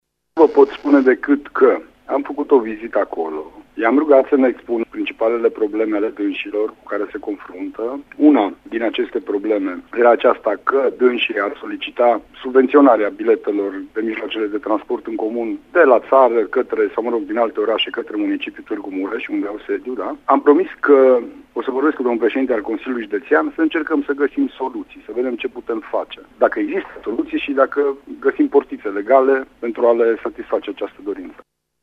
Prefectul judeţului Mureş, Lucian Goga, a explicat că nu a putut face promisiuni concrete, dar că va discuta cu preşedintele CJ Mureş pentru a vedea dacă există o soluţie legală: